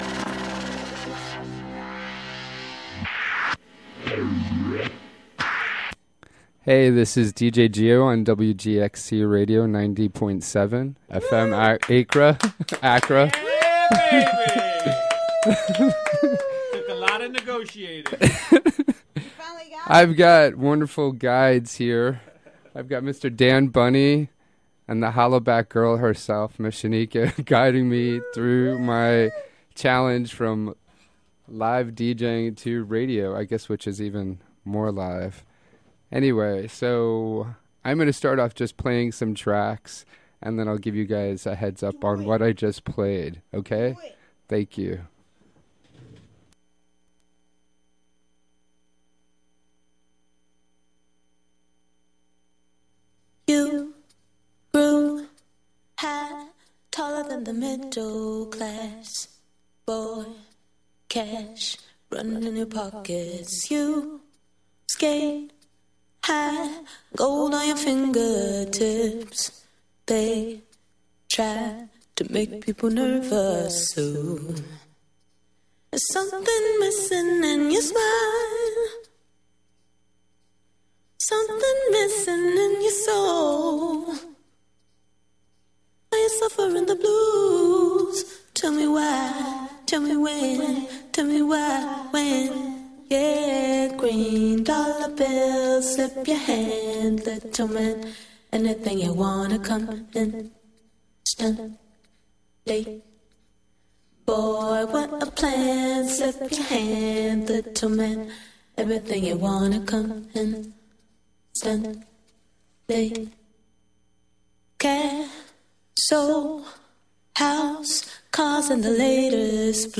Recorded live on WGXC 90.7-FM.